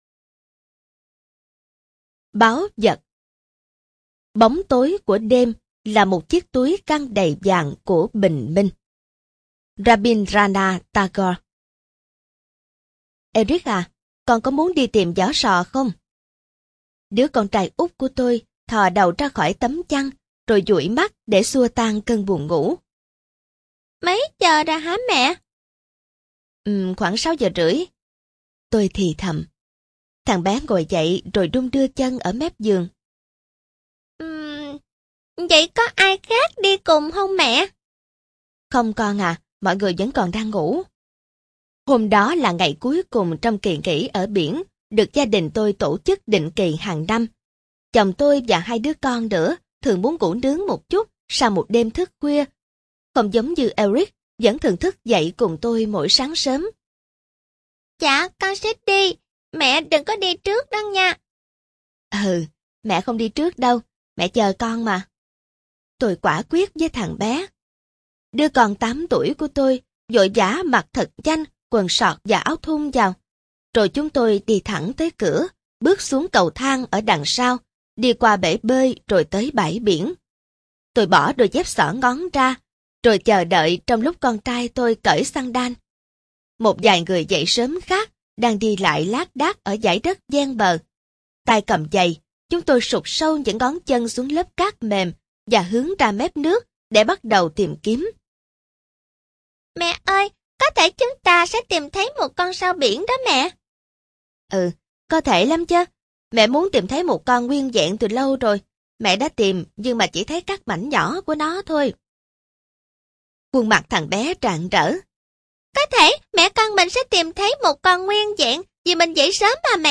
Sách nói Chicken Soup 26 - Ký Ức Biển - Jack Canfield - Sách Nói Online Hay